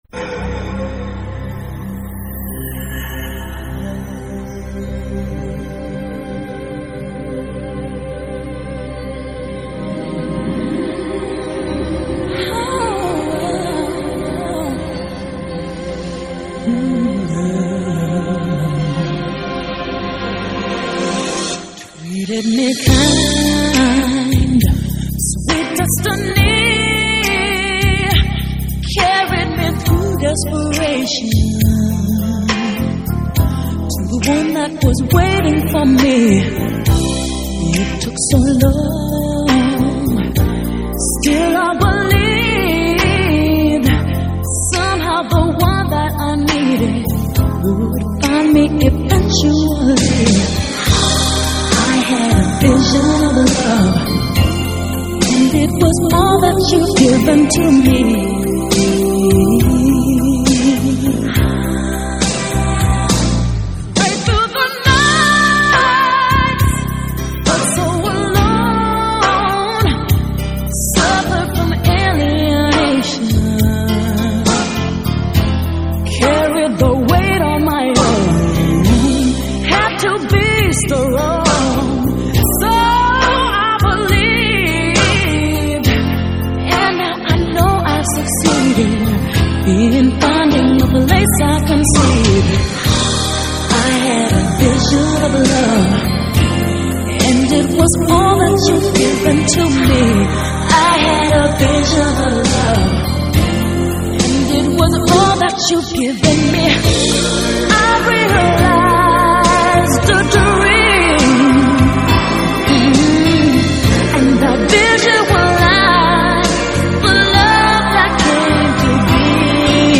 با ویسل رجیستر و ملودی‌های قدرتمند
Pop, R&B, Ballad